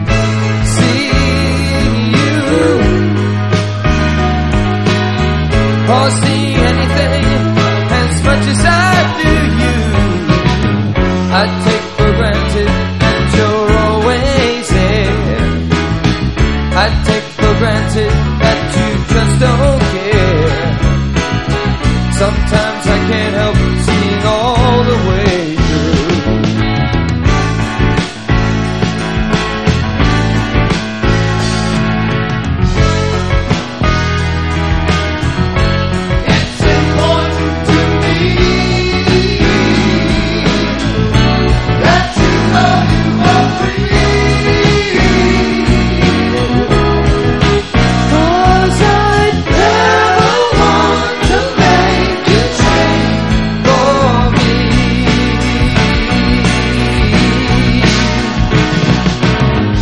ROCK / S.S.W./A.O.R.
なんと原曲と寸分違わぬ、ほとんど完全コピー！